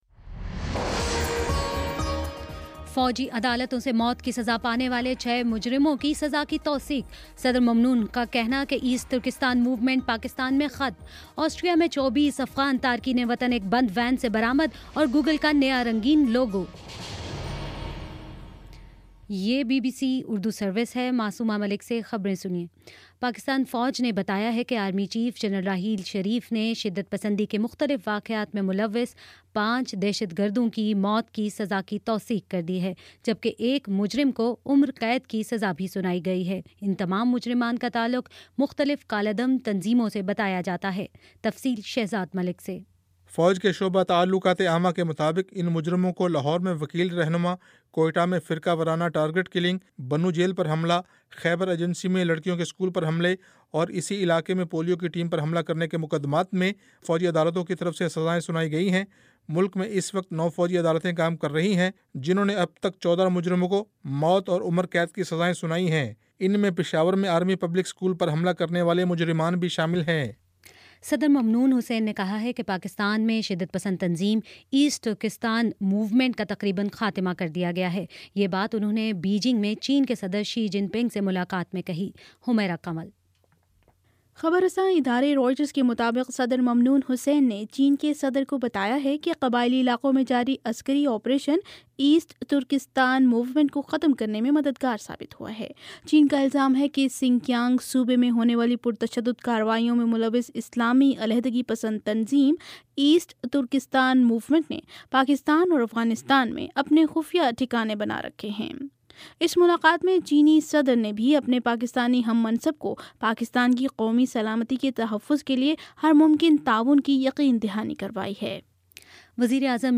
ستمبر 2: شام سات بجے کا نیوز بُلیٹن